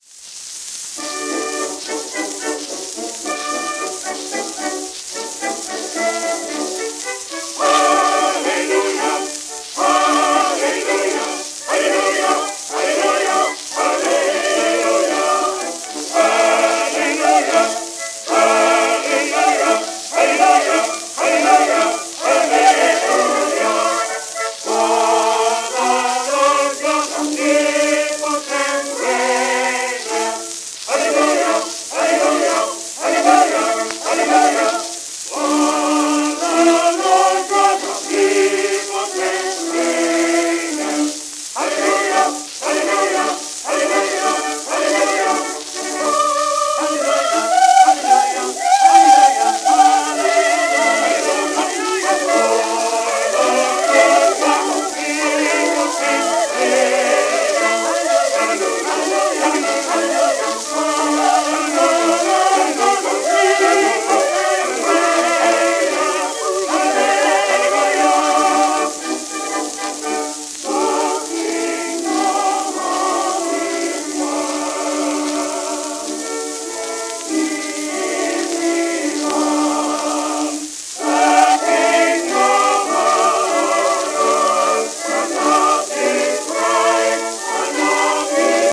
w/オーケストラ
旧 旧吹込みの略、電気録音以前の機械式録音盤（ラッパ吹込み）